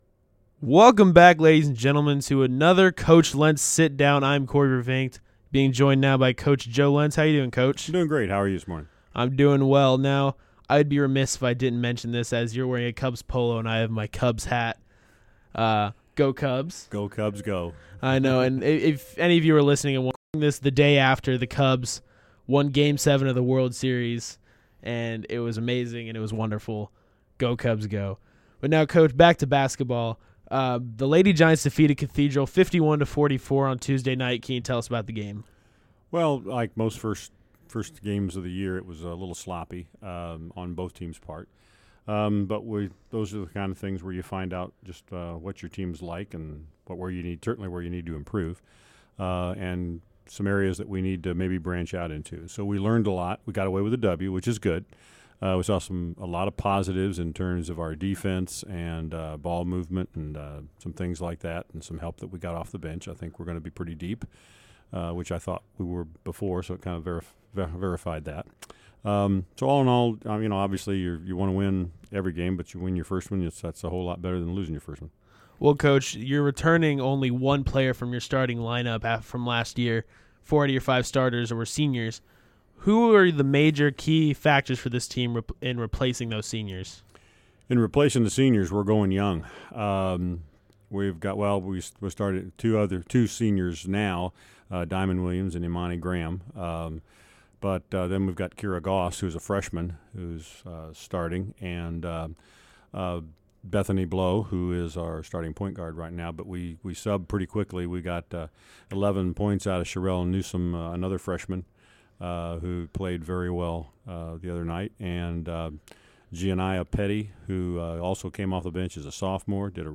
Stand alone interview